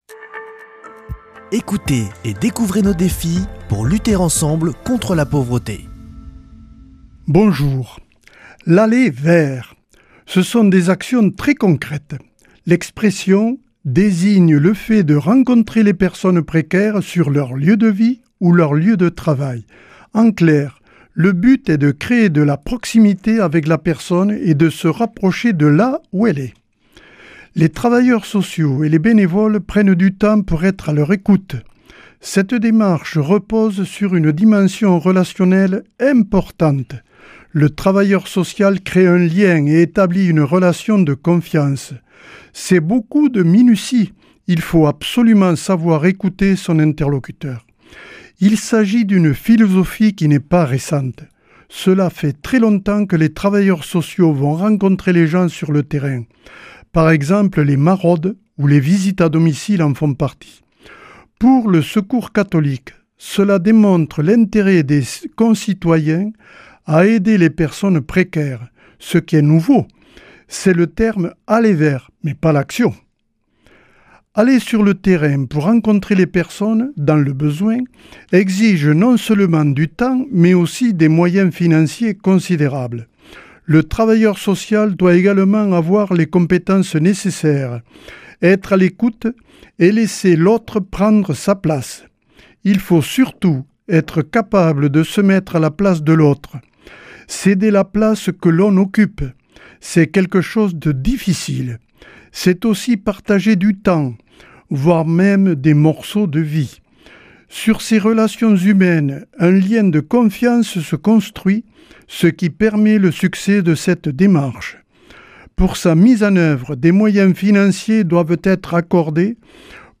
samedi 31 août 2024 Chronique du Secours Catholique Durée 3 min